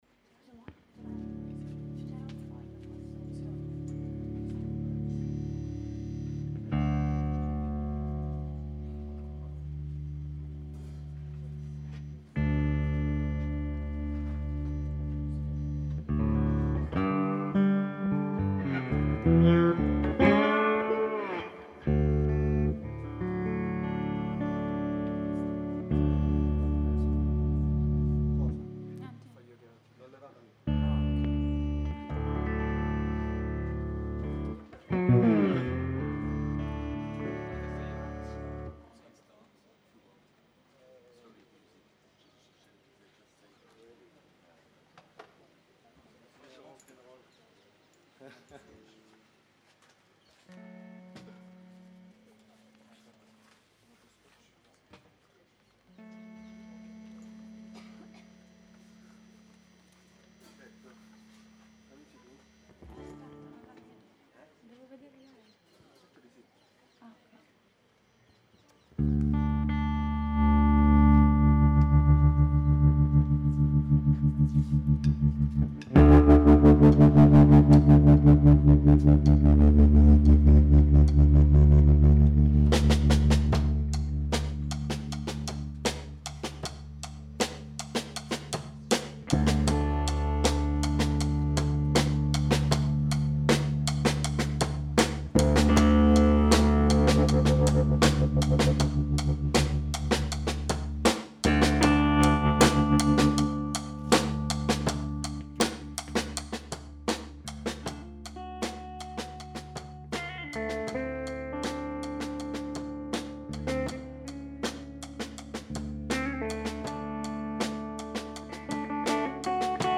au jardin des plantes à Montpellier
accompagnée musicalement par le groupe palermitain